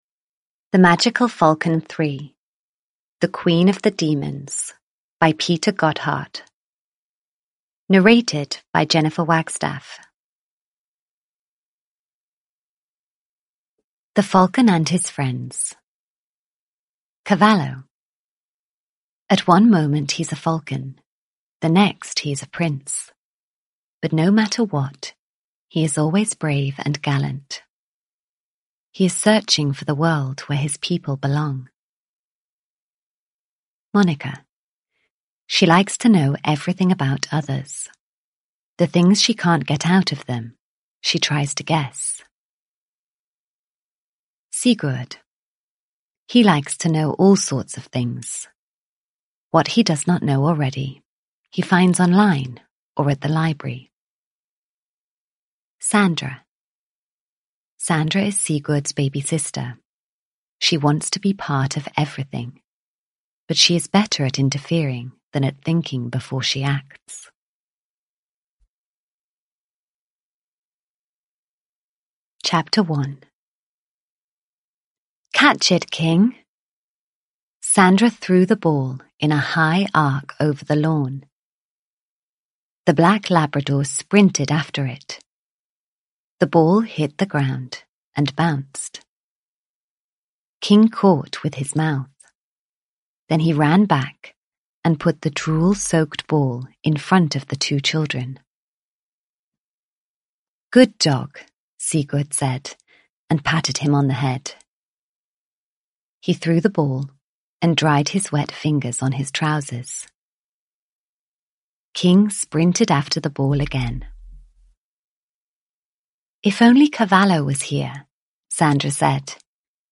The Magical Falcon 3 - The Queen of the Demons (ljudbok) av Peter Gotthardt